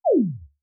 SFX_ItemDrop_02.wav